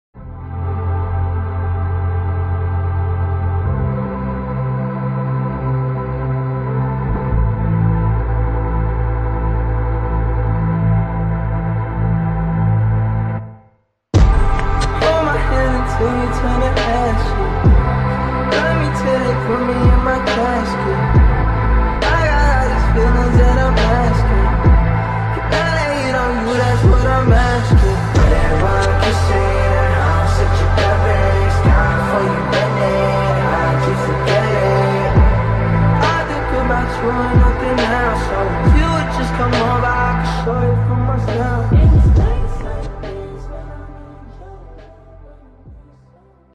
Malta during Fireworks season = sound effects free download